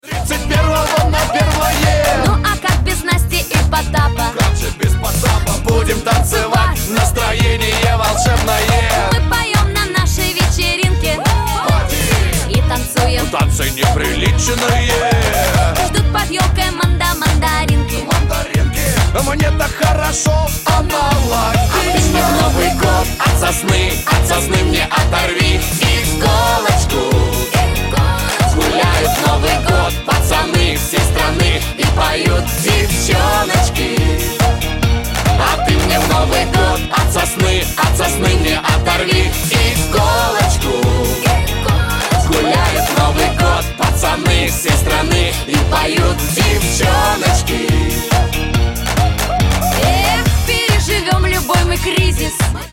поп
зажигательные
веселые
праздничные